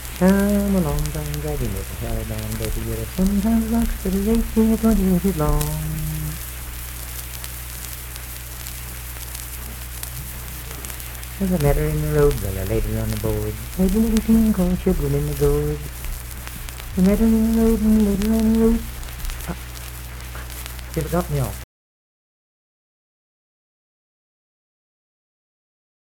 Unaccompanied vocal music performance
Bawdy Songs
Voice (sung)